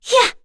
Kara-Vox_Attack2.wav